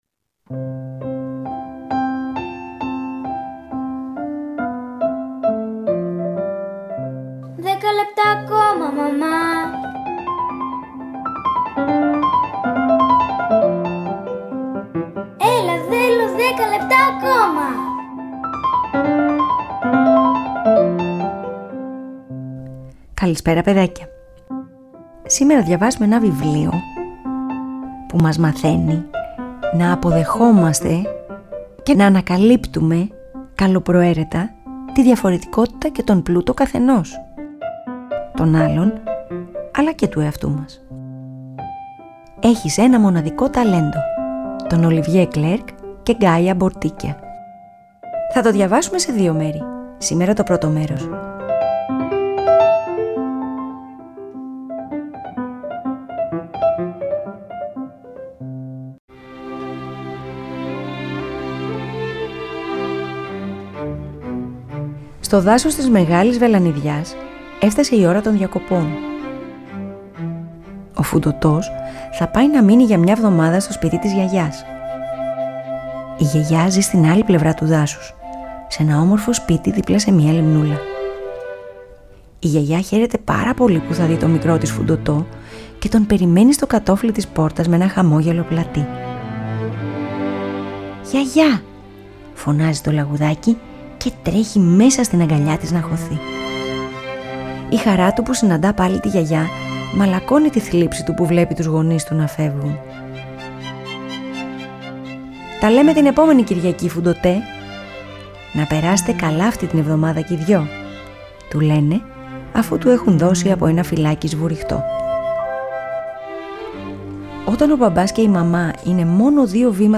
ΠΑΡΑΜΥΘΙΑ